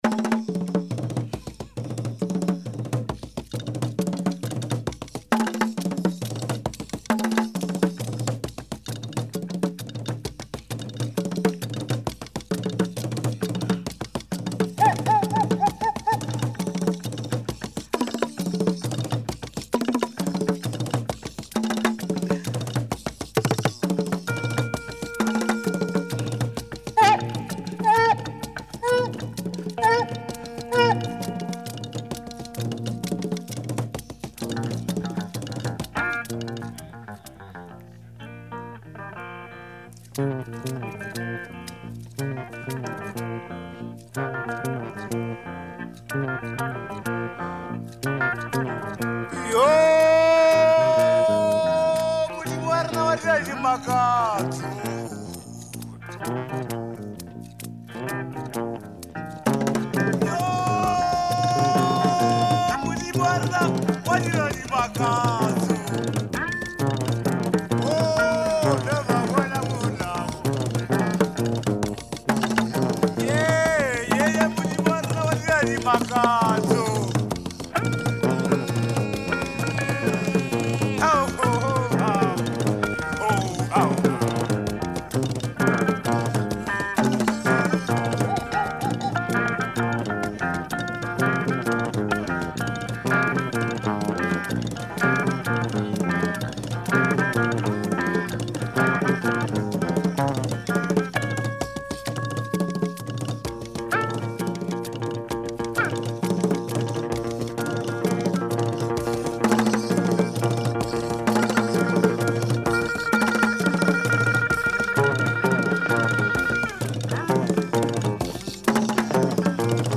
Great afro jazz
incorporating local elements of music.